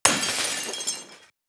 sfx_window_break_big_1.wav